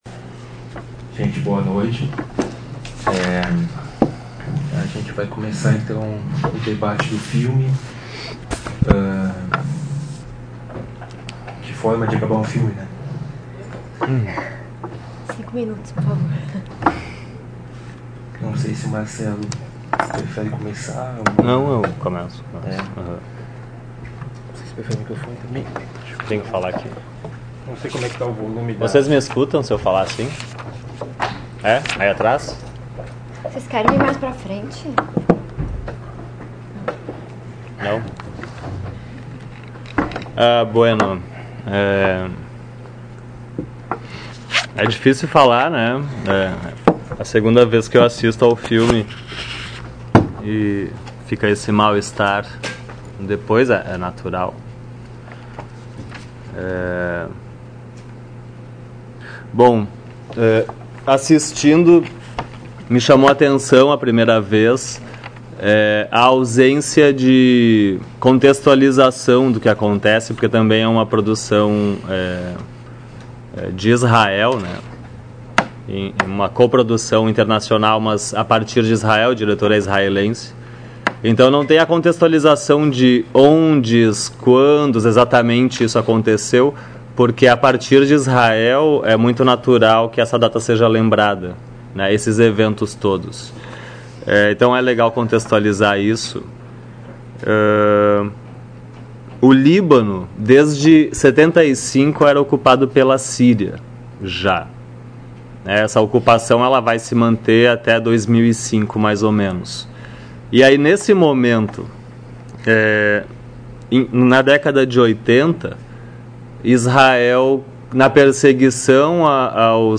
Comentários dos debatedore(a)s convidado(a)s
na sessão de exibição e debate do filme "Valsa com Bashir"